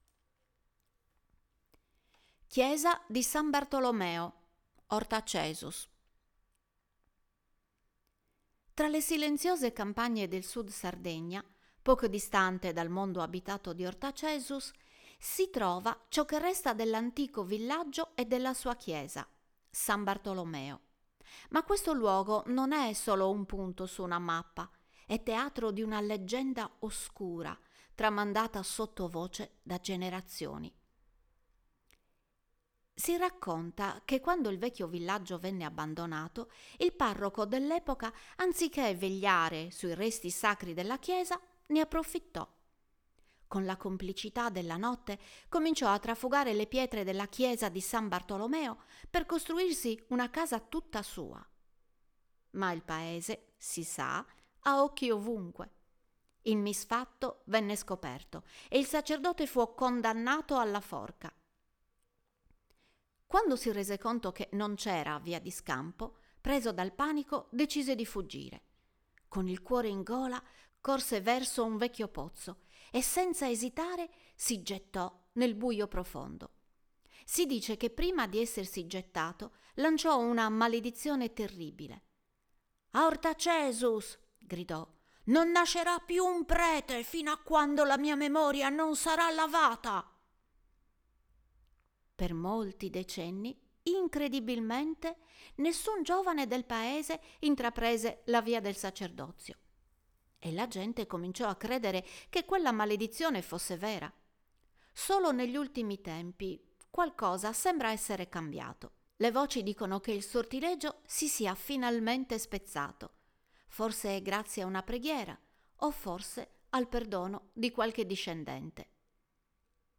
Voce Narrante